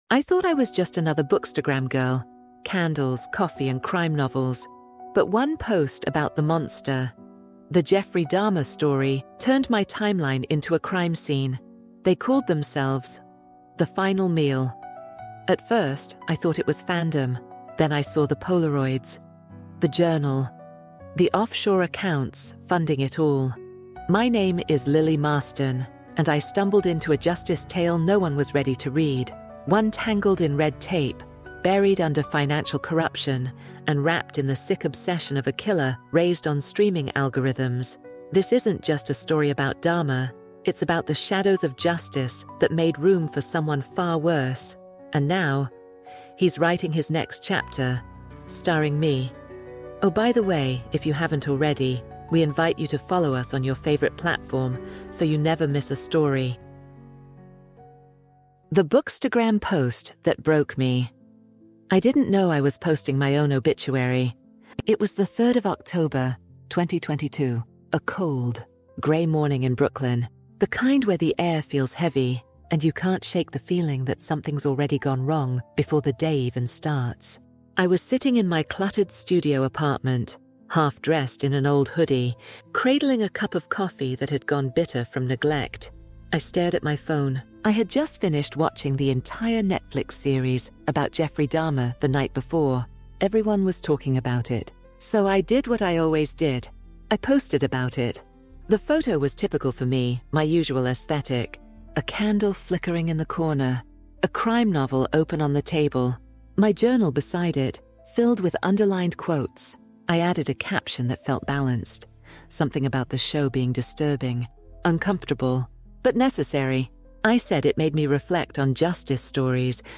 True Crime | Shadows of Justice EP1 | The Bookstagram Post That Broke Me Audiobook